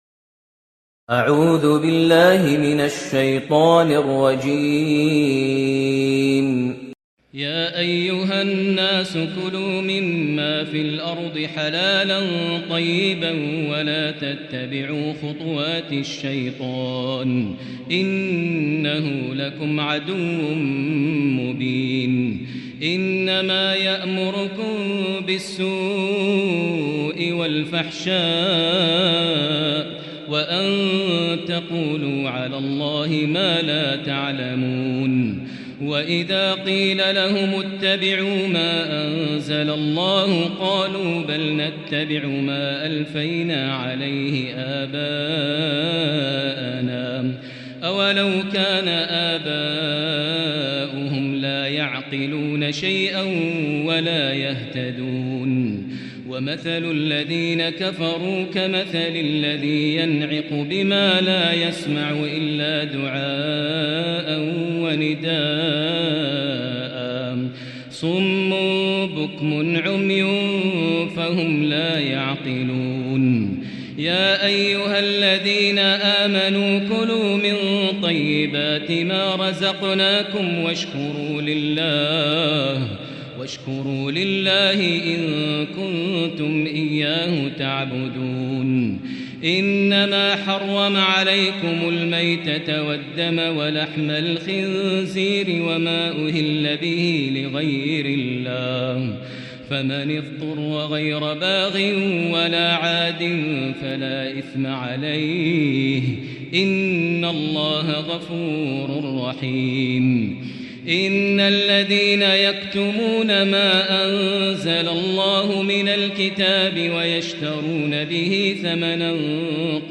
Ramadan 1444H > Taraweh 1444H > Taraweeh - Maher Almuaiqly Recitations